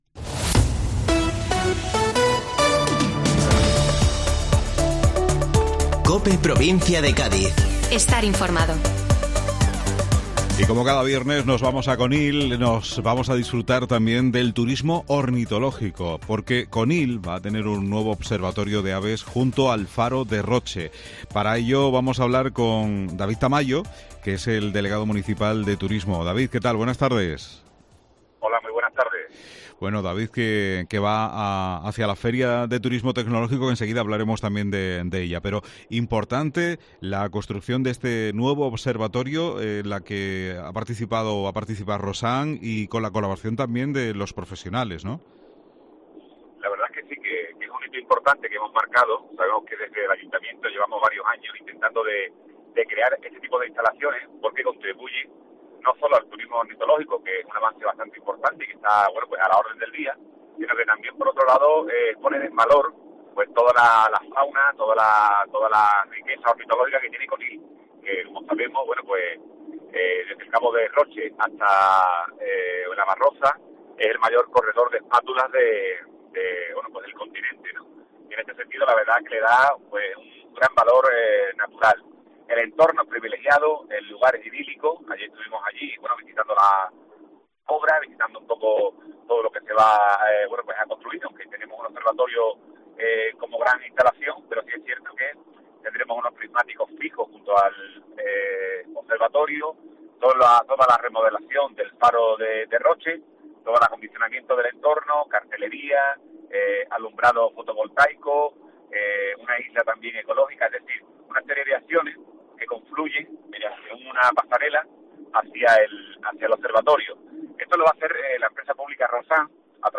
David Tamayo, Delegado de Turismo del Ayuntamiento de Conil habla del nuevo observatorio de aves que se ubica junto al Faro de Roche.